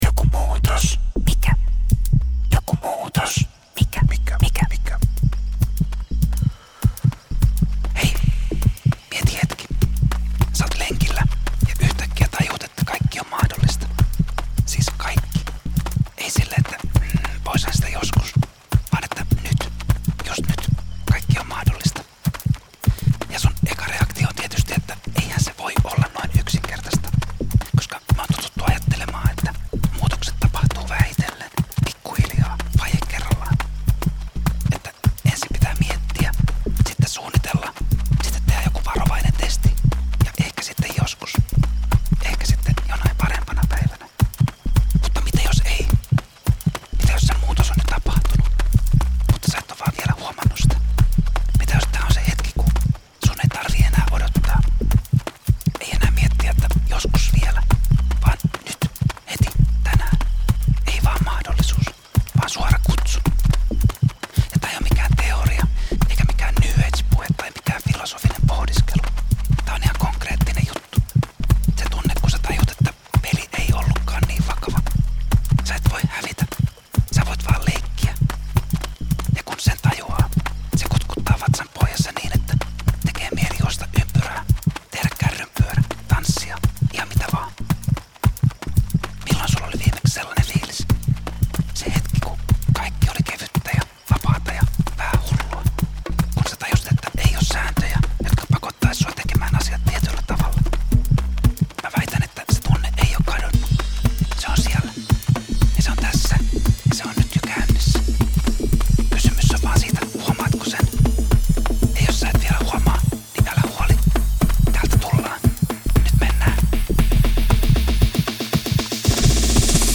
Tässä jaksossa yhdistyy energinen kuiskausääni, nopearytmiset trigger-äänet ja nouseva musiikki, joka kuljettaa sinut oivalluksen ja voimaantumisen hetkeen. Fast ASMR -tyylillä toteutetussa podcastissa ei jäädä paikoilleen. Tempomme kiihtyy, ja pääset mukaan ainutlaatuiseen äänielämykseen, jossa kaikki on mahdollista juuri nyt.
Perinteisestä ASMR:stä tuttu kihelmöivä rauhoittuminen saa tässä jaksossa uuden energisen ulottuvuuden: rytmi, kuiskaukset ja nopeat äänielementit herättävät kehon ja mielen liikkeeseen. Voit kokea, kuinka muutos ei aina vaadi suunnittelua tai analyysiä.